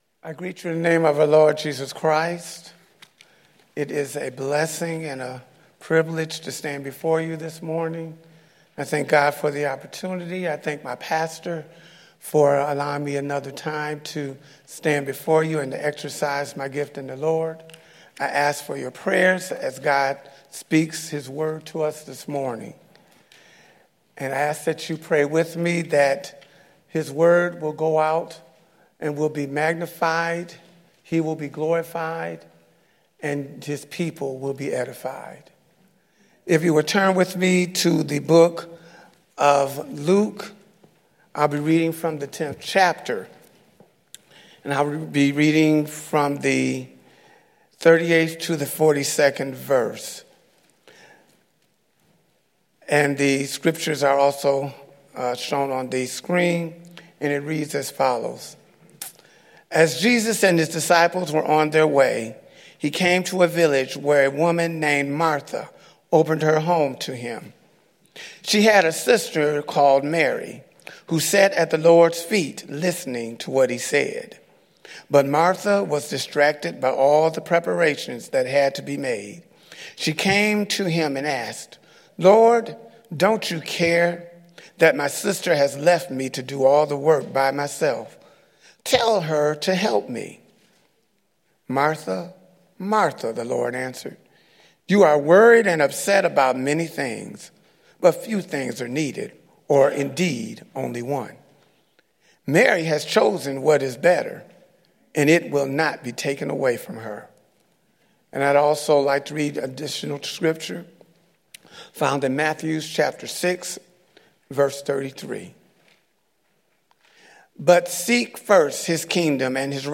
2014-04-20-Sermon- TheWorlds Greatest Step1